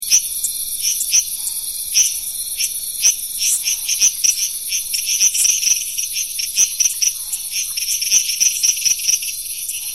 Males call from low emergent bushes and grass in ponds.
Call is a cricketlike sound "shreek, shreek, shreek" repeated at frequent intervals.